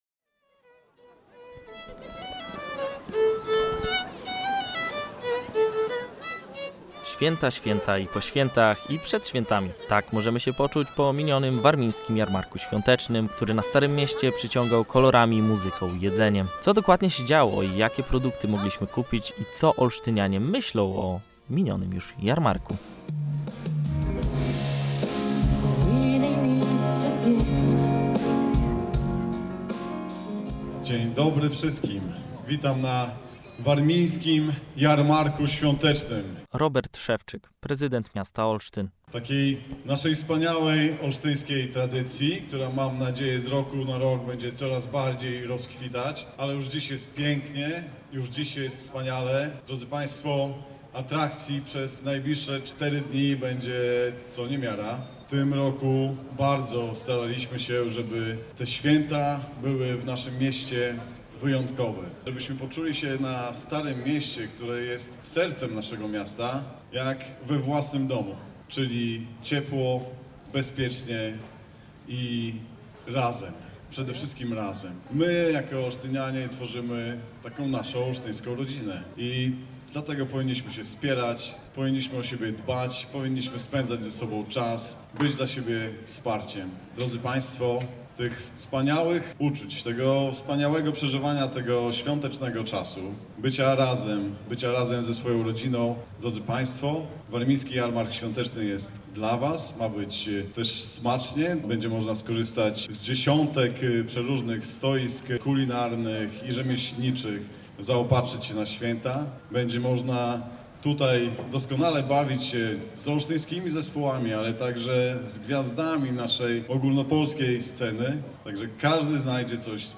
1612-jarmark-swiateczny-relacja.mp3